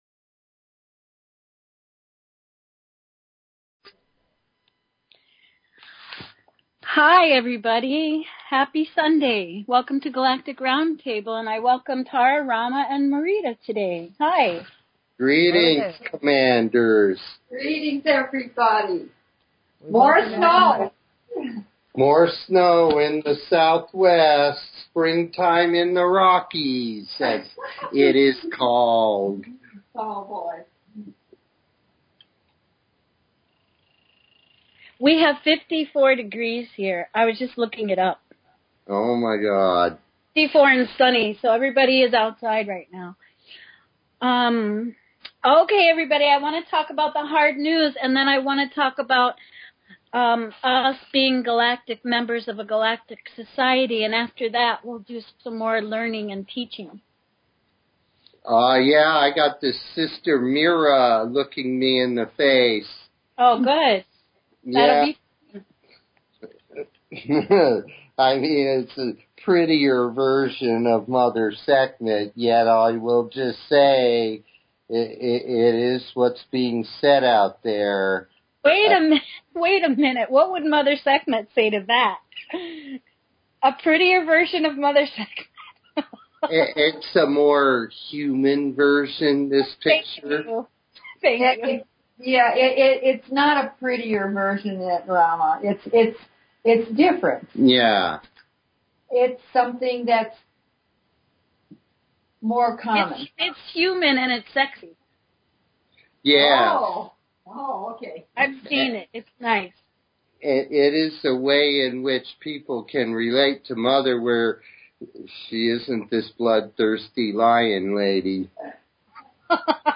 Talk Show Episode, Audio Podcast, The_Galactic_Round_Table and Courtesy of BBS Radio on , show guests , about , categorized as